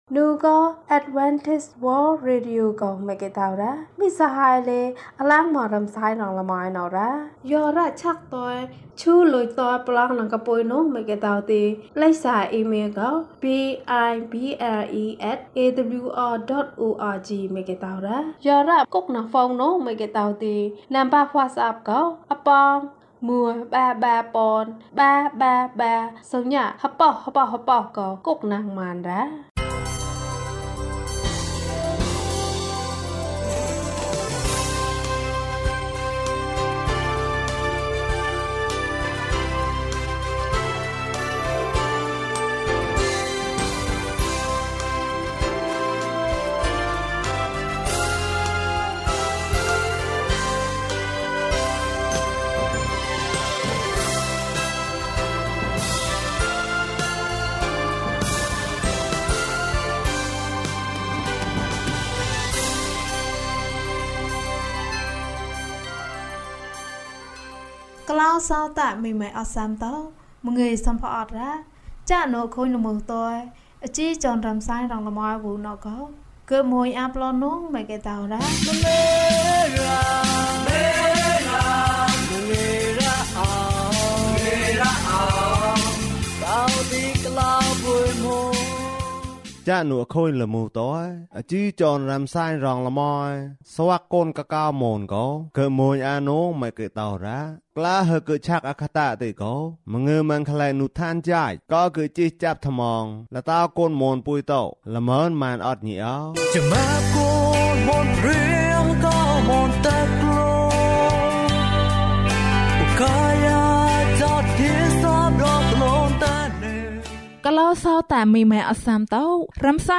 ဘဝသစ်။၀၂ ကျန်းမာခြင်းအကြောင်းအရာ။ ဓမ္မသီချင်း။ တရားဒေသနာ။